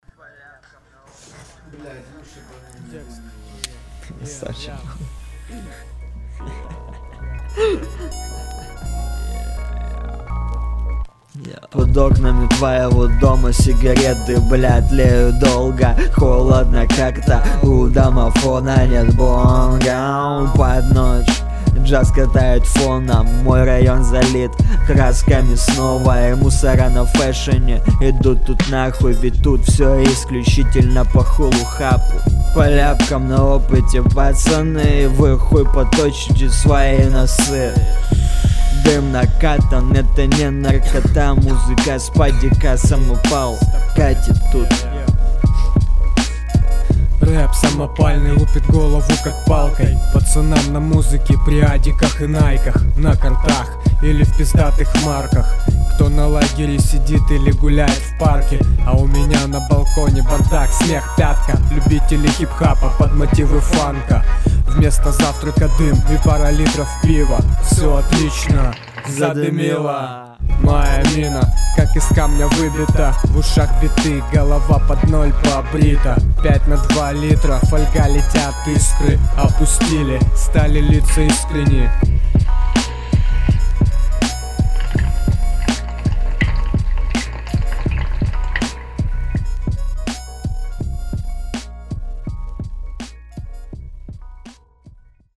Яркий андерграунд-проект из города-героя Одессы.
Очень яркий и талантливый хип-хоп.